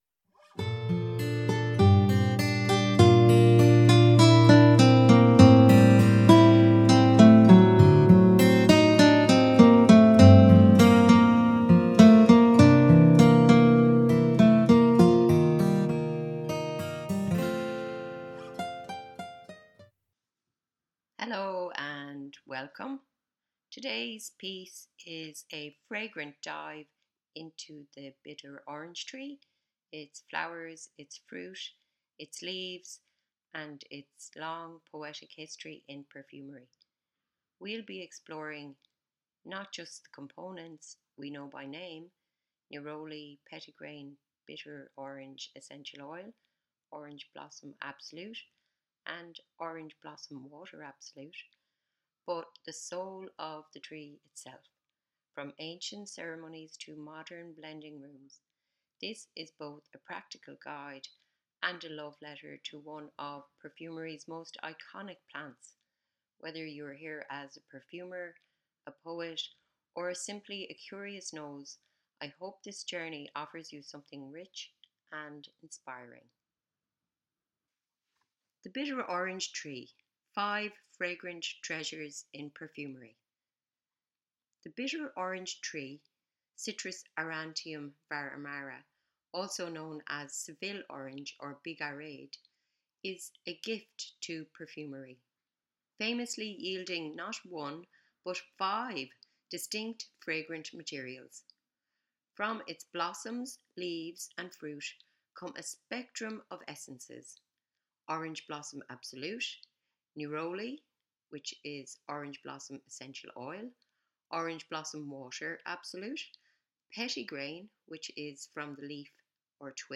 This is a quiet study hour and a special bonus episode—a one-hour reading for anyone who wants to learn and reflect.